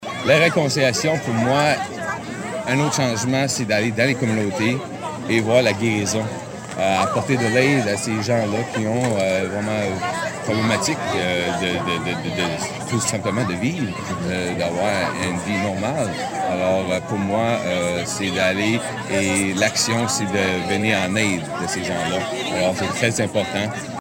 Près de 200 personnes, dont beaucoup d’enfants, se sont ainsi réunies ce matin afin de se souvenir des victimes des pensionnats et de se réconcilier dans le but de construire la société de demain.
Mais le mot final revient à Terry Shaw, chef de la nation Micmac de Gespeg, qui estime qu’il faut reconnaître le mal qui a été fait et surtout venir en aide à ceux et celles qui vivent avec les séquelles du passé.